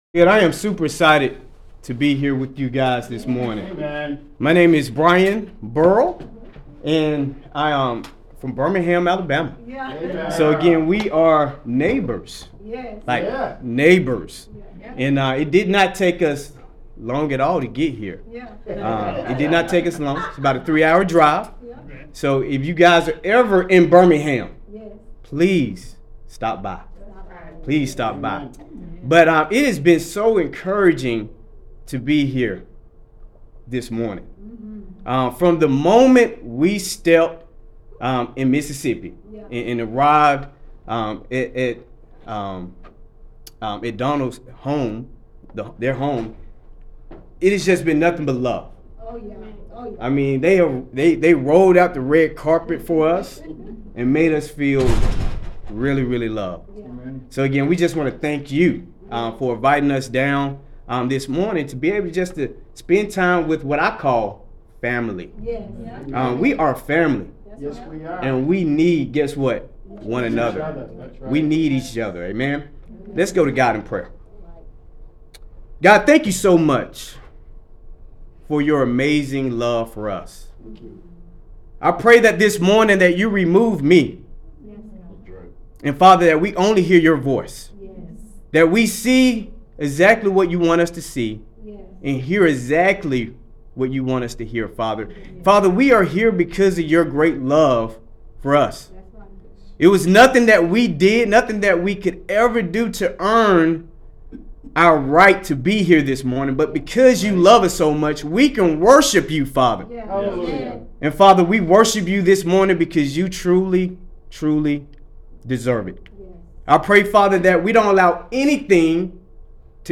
Sermons | Tri-County Church